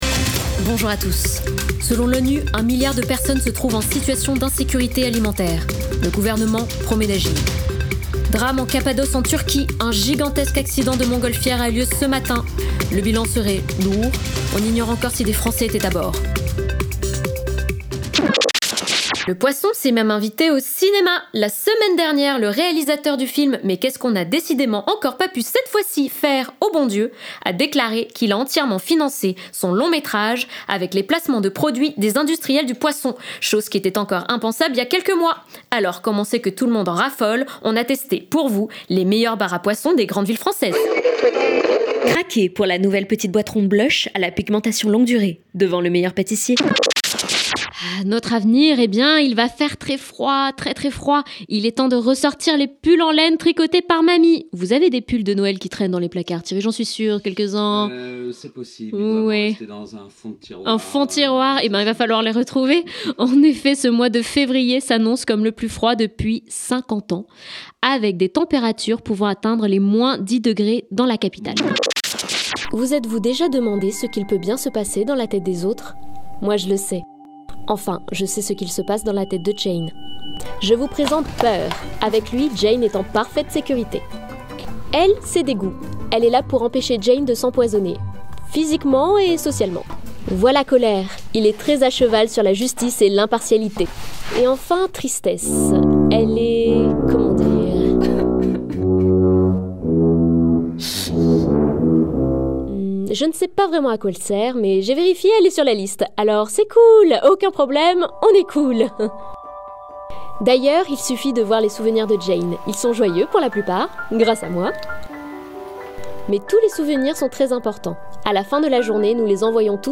Bandes-son
18 - 30 ans - Mezzo-soprano